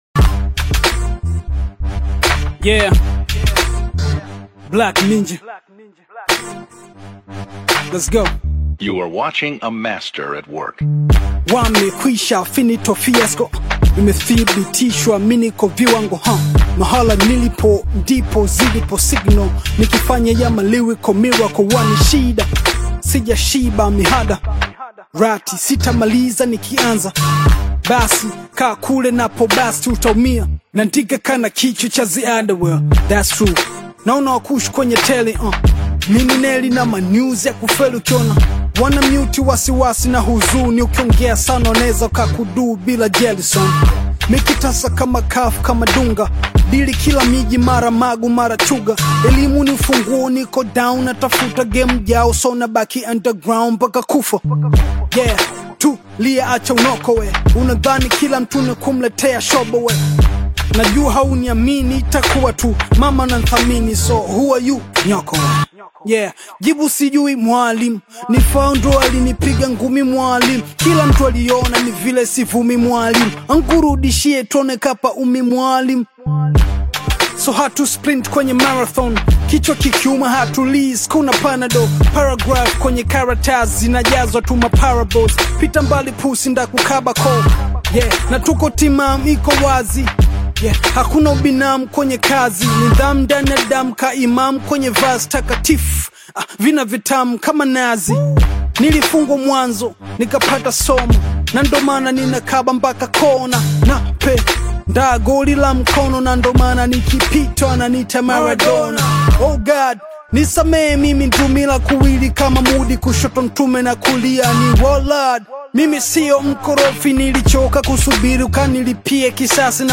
Tanzanian hip-hop single
thought-provoking Swahili verses